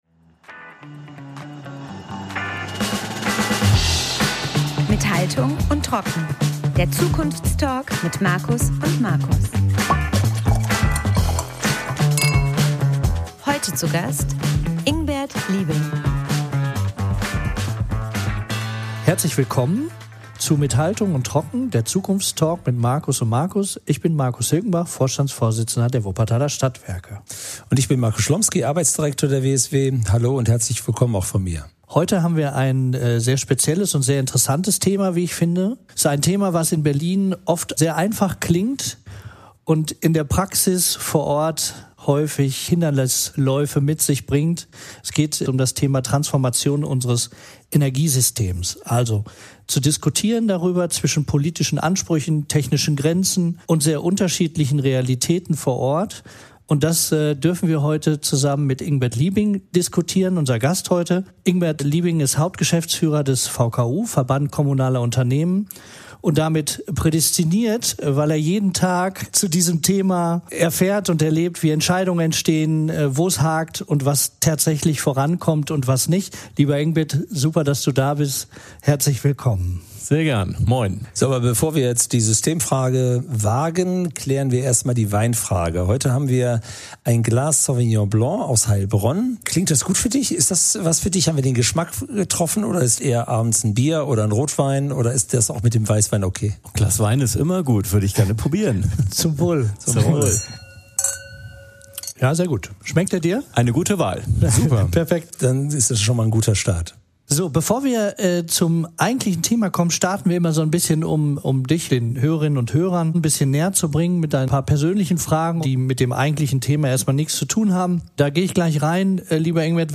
Ein Gespräch über Ambitionen, Realitäten und Verantwortung und darüber, wie Transformation gelingen kann, wenn Politik, Branche und Technik wieder stärker zusammengedacht werden.